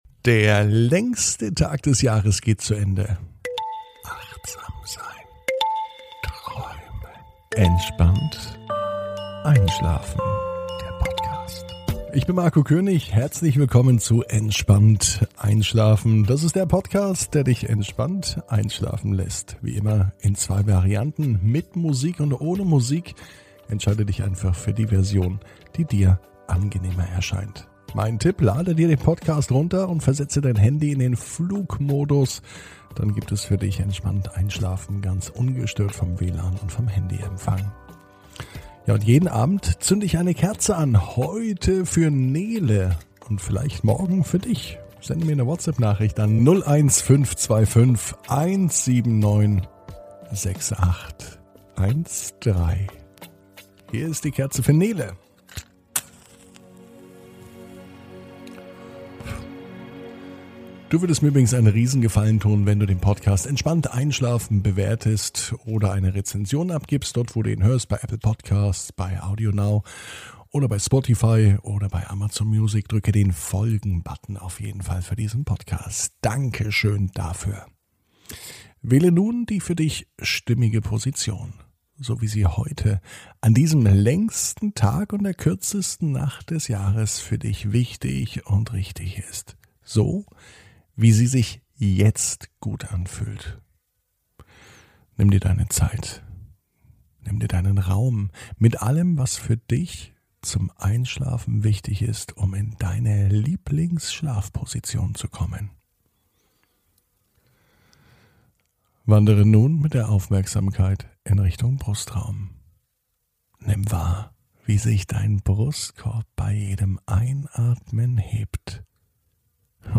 (ohne Musik) Entspannt einschlafen am Montag, 21.06.21 ~ Entspannt einschlafen - Meditation & Achtsamkeit für die Nacht Podcast